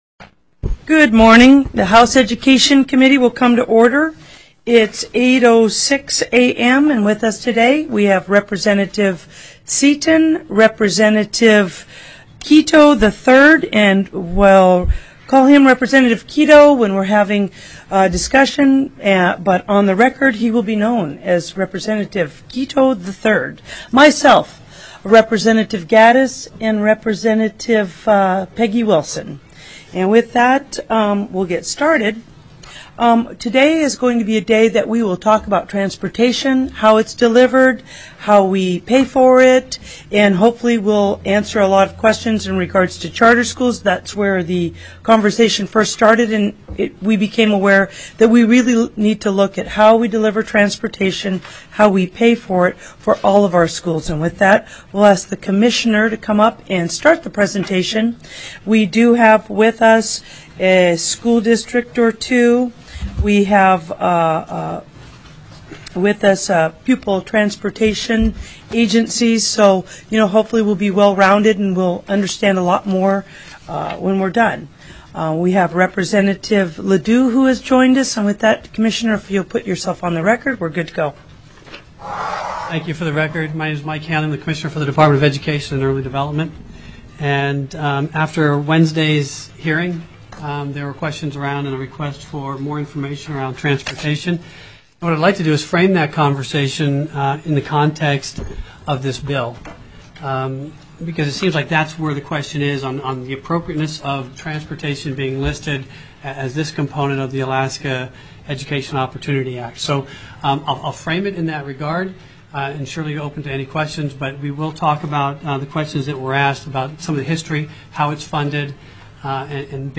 TELECONFERENCED